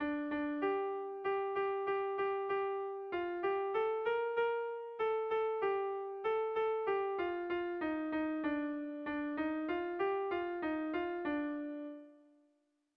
Air de bertsos - Voir fiche   Pour savoir plus sur cette section
Haurrentzakoa
Lau puntuko berdina, 8 silabaz
ABDE